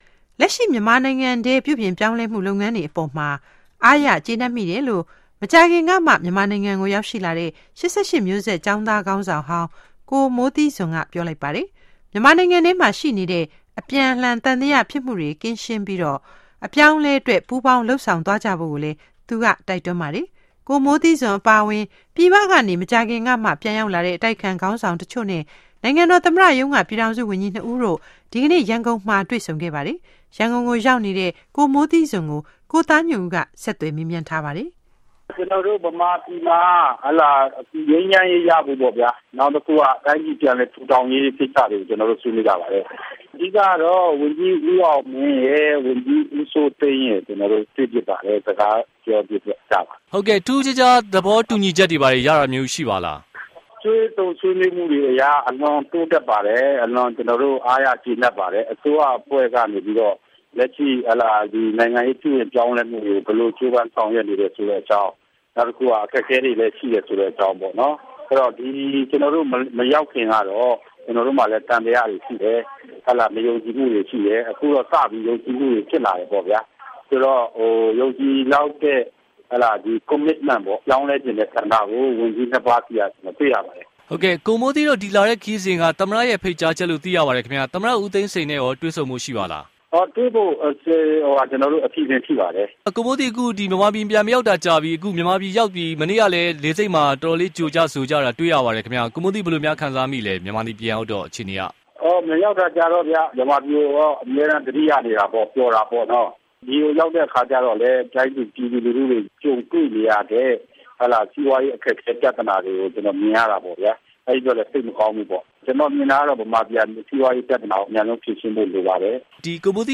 Moe Thee Zun Interview